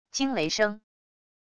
惊雷声wav音频